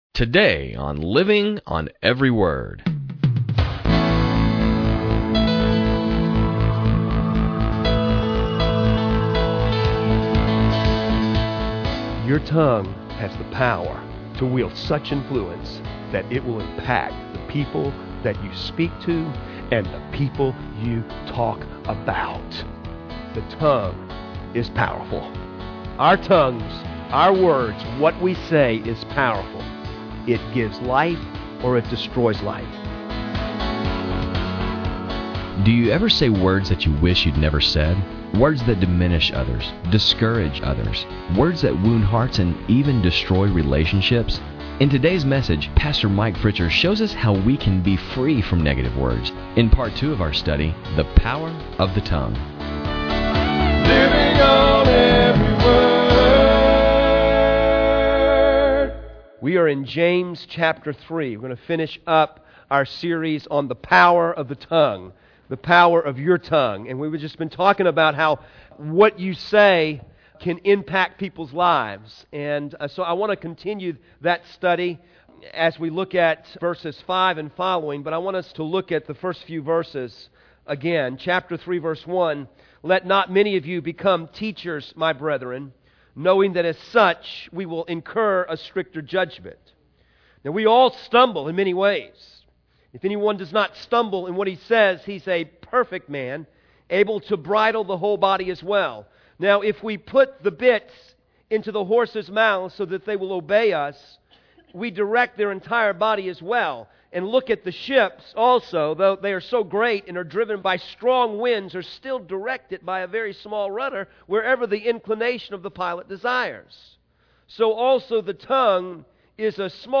sermons on cd